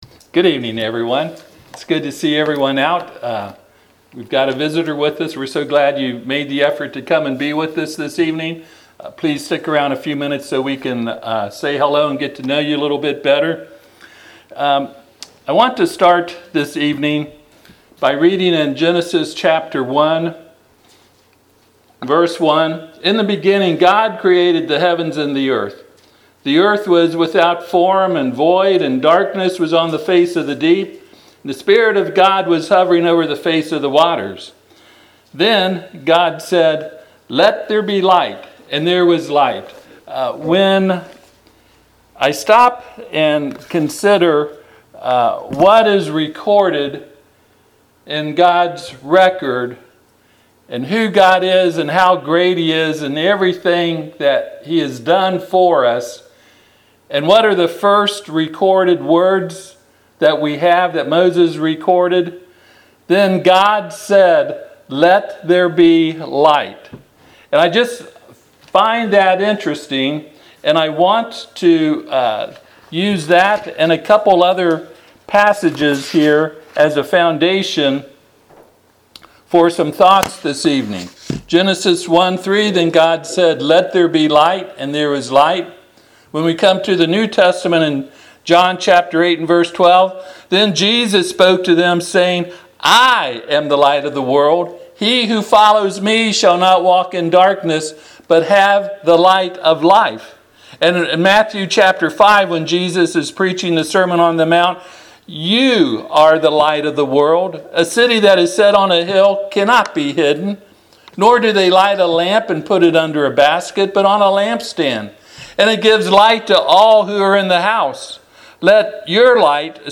Passage: Genesis 1:1-3 Service Type: Sunday PM